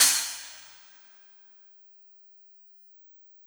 Cymbol Shard 03.wav